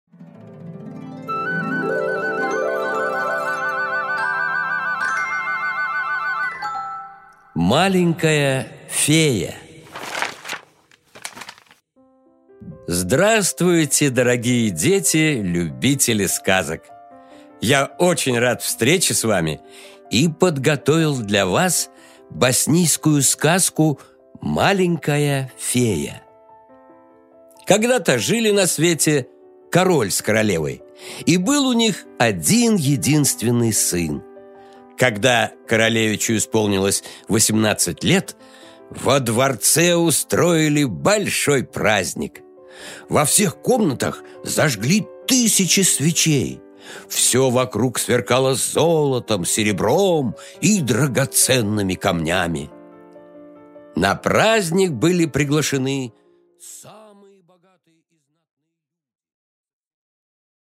Аудиокнига Маленькая фея | Библиотека аудиокниг